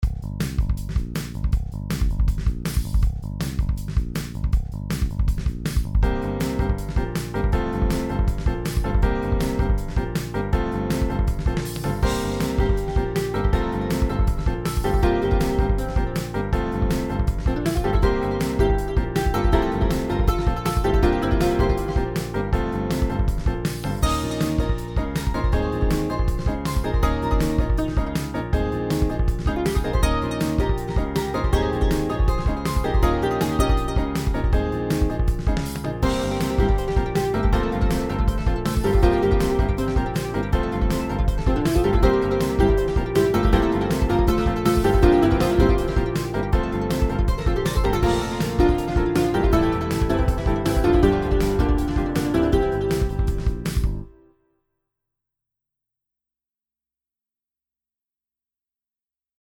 A groovy, driving song.
• Music requires/does smooth looping